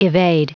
Prononciation du mot evade en anglais (fichier audio)